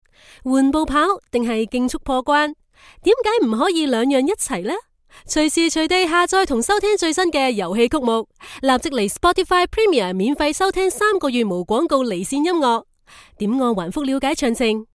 Female
Natural, engaging, authentic, with strong professional delivery
Spotify Music Promos